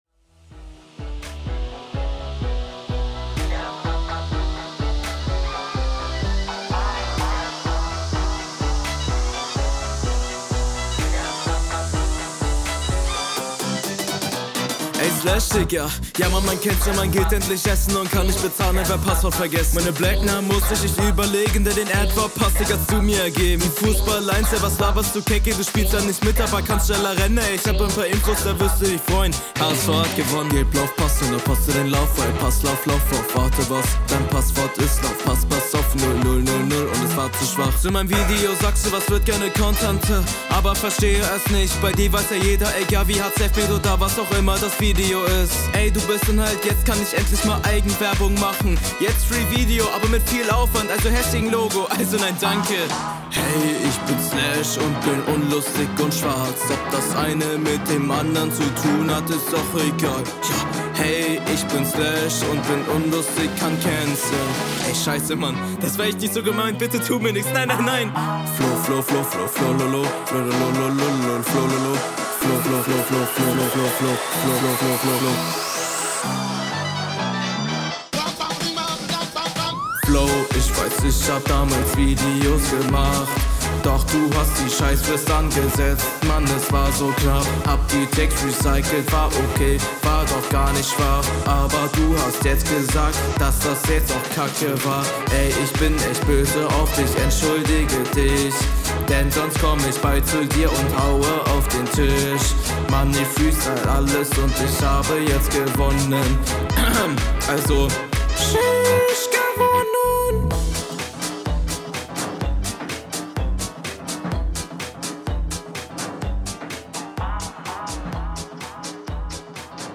hook ist katastophe deswegen so wenig bei hörgenuss und der beat ist unendlich kacke
Zu vielen höhen beim mix.